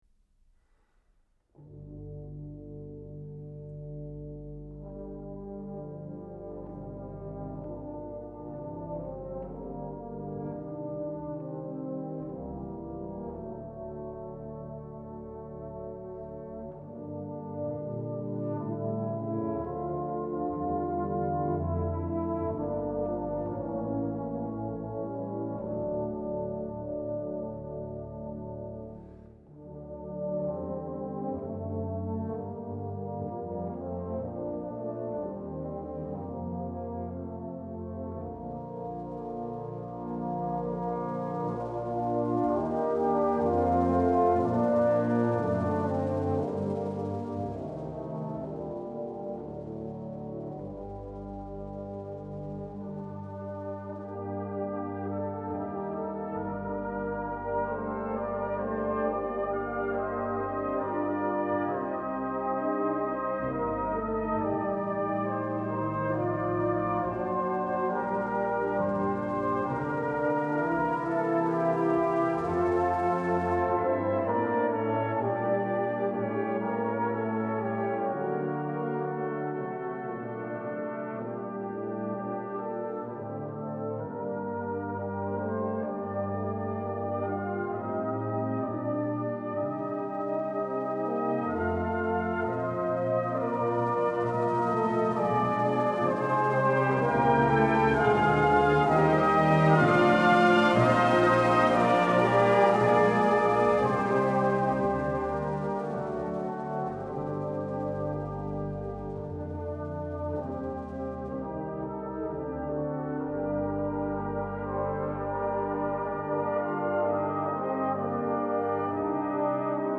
It’s based around a 16th century chorale